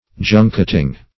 Junketing \Jun"ket*ing\, n.